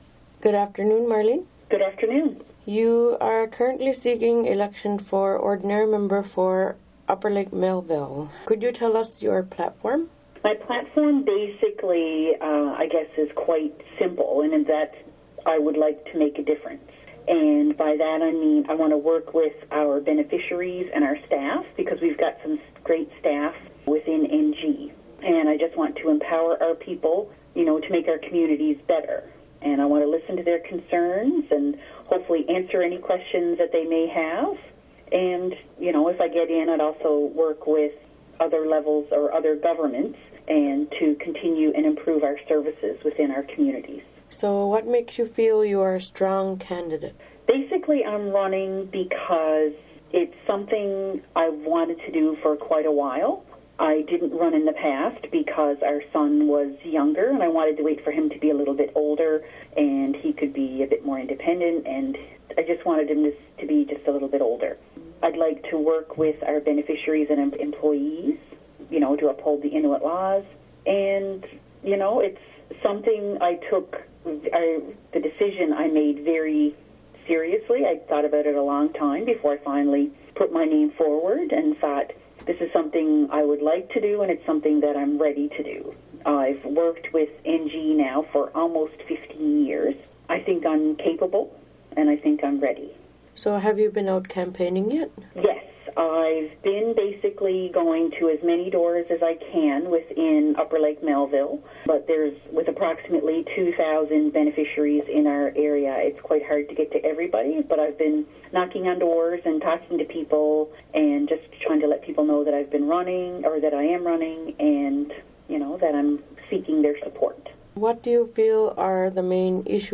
Candidates Running For Ordinary Member Speak Out.